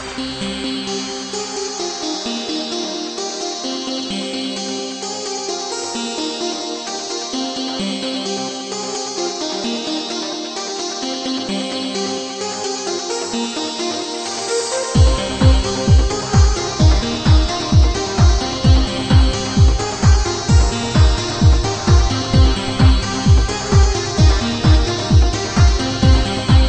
Idea need this awesome track ID'd killer synth line...